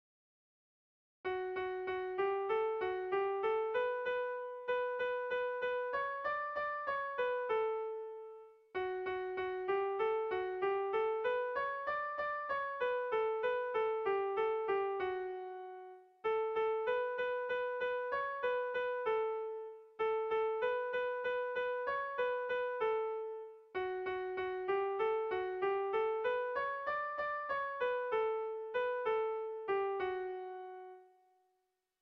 Kontakizunezkoa
Zortziko handia (hg) / Lau puntuko handia (ip)
A1A2BA2